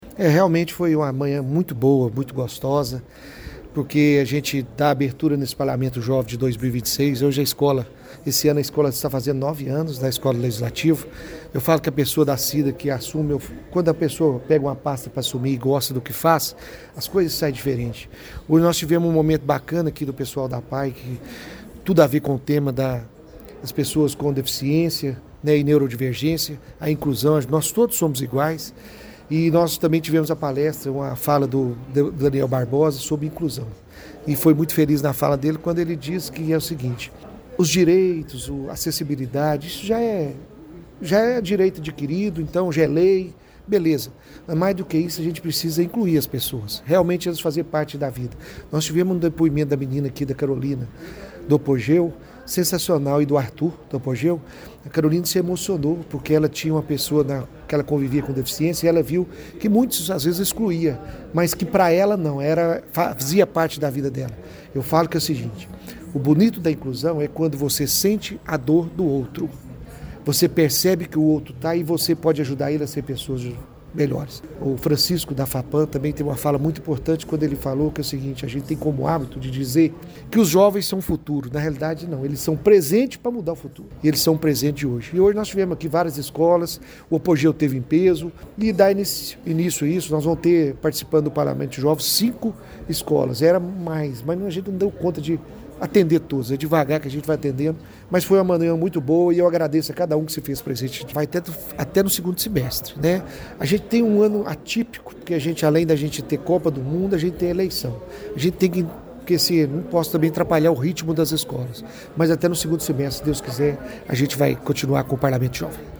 O presidente da Mesa Diretora do Legislativo paraminense, vereador Geraldo Magela de Almeida, o Geraldinho Cuíca (PSDB), destacou a importância da iniciativa por aproximar o universo político da educação e incentivar a participação dos jovens na vida pública.